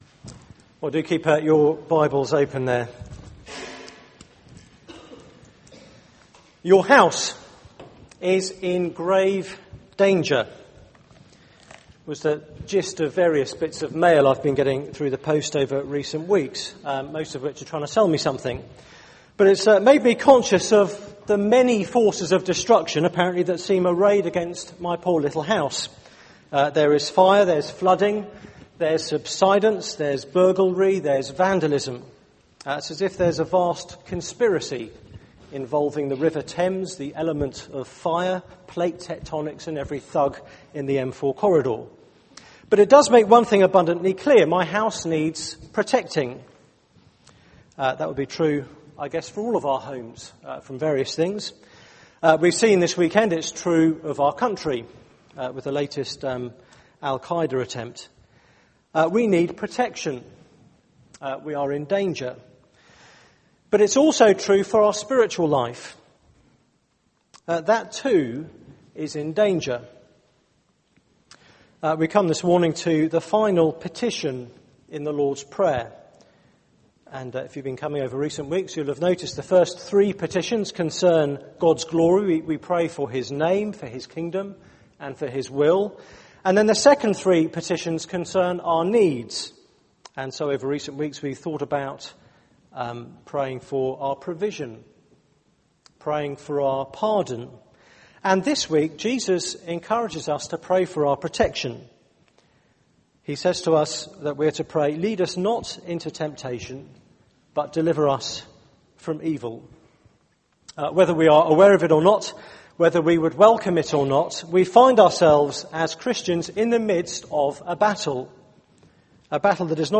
Media for 9:15am Service on Sun 31st Oct 2010
Sermon Search the media library There are recordings here going back several years.